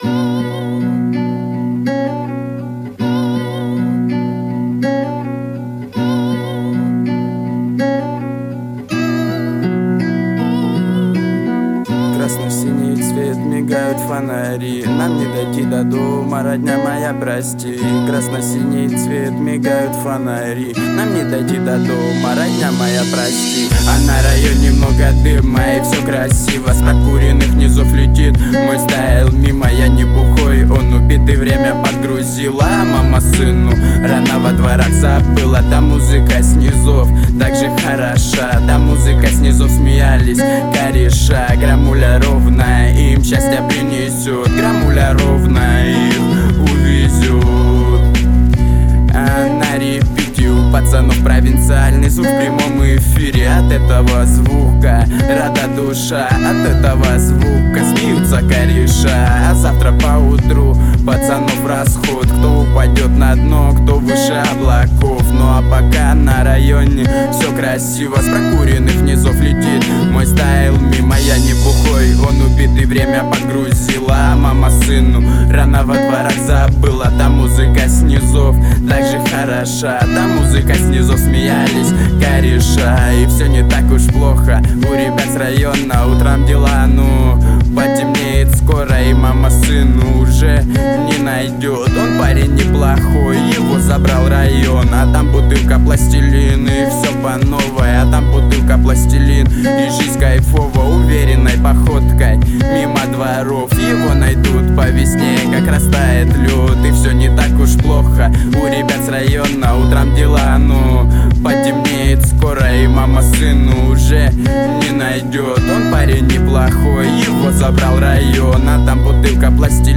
это трек в жанре поп-рок